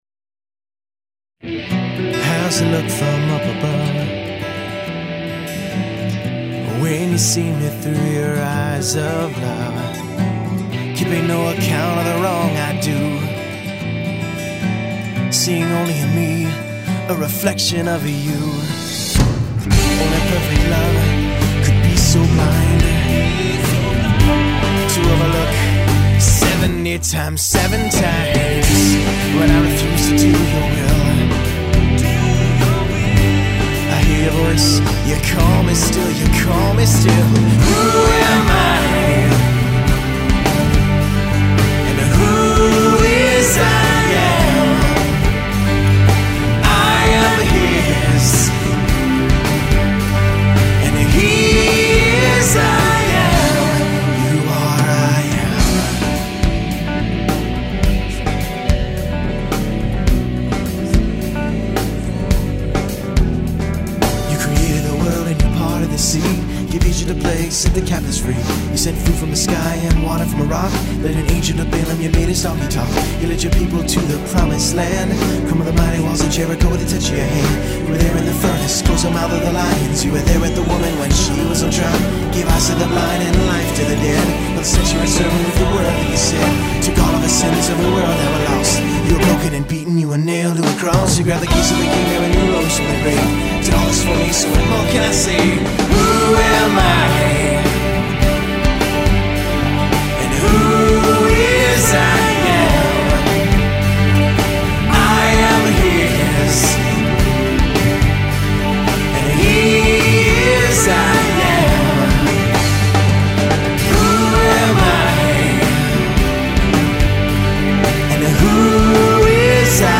guitar driven